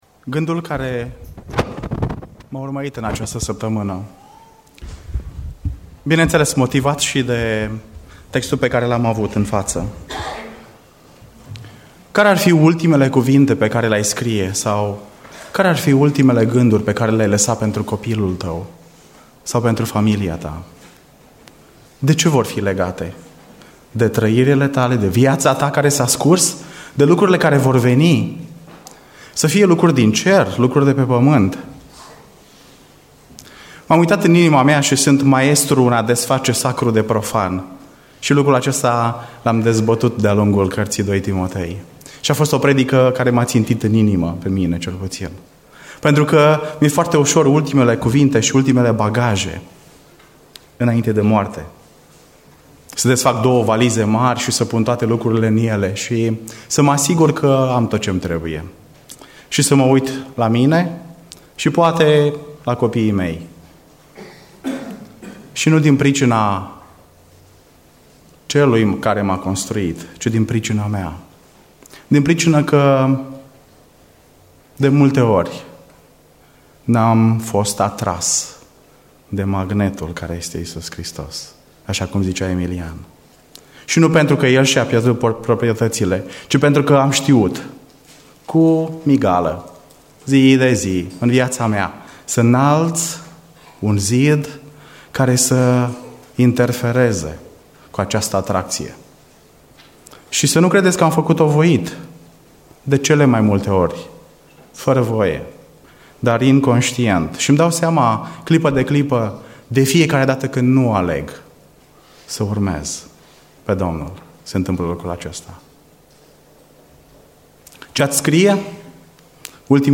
Predica Exegeza 2 Timotei 4